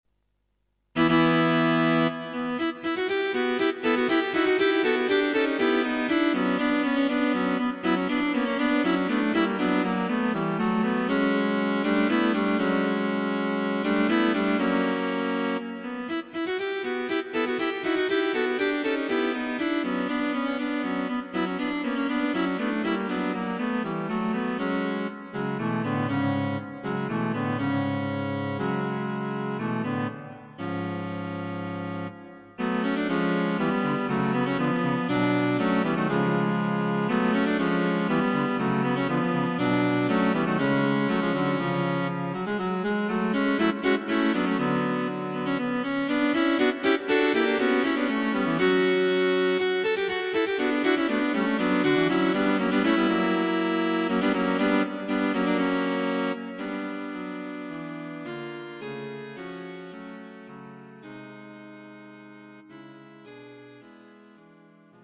Cello Trio
The first section is an opening flourish or fanfare
exploring some harmonic modulations.